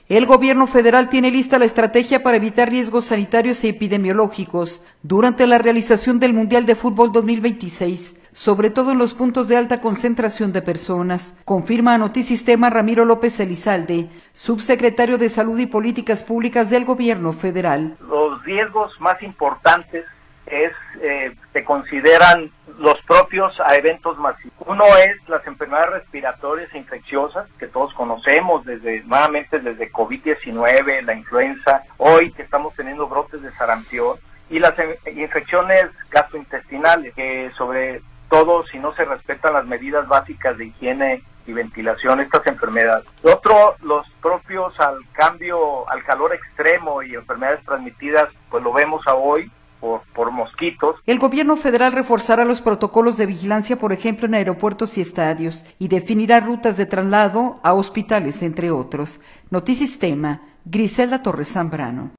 El gobierno federal tiene lista la estrategia para evitar riesgos sanitarios y epidemiológicos durante la realización del mundial de futbol 2026, sobre todo en los puntos de alta concentración de personas, confirma a Notisistema Ramiro López Elizalde, subsecretario de salud y políticas públicas del gobierno federal.